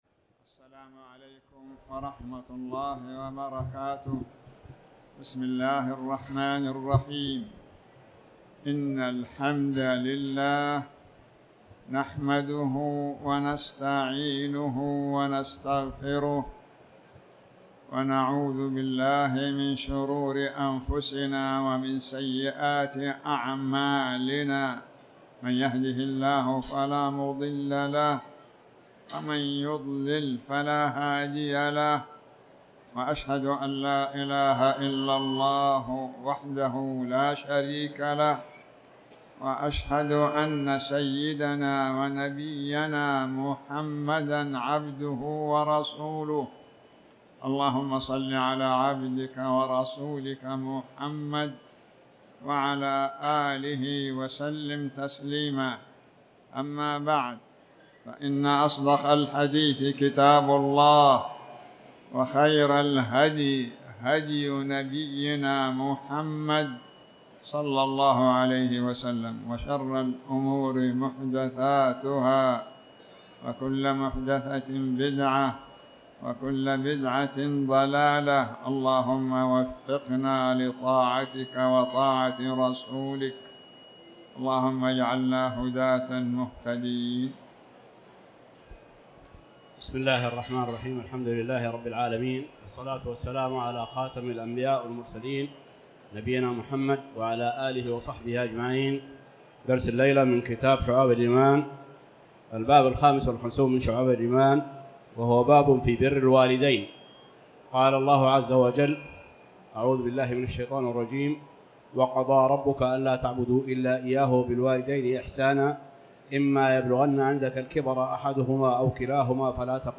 تاريخ النشر ٨ شعبان ١٤٣٩ هـ المكان: المسجد الحرام الشيخ